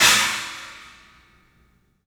Index of /90_sSampleCDs/E-MU Producer Series Vol. 5 – 3-D Audio Collection/3DPercussives/3DPACymbals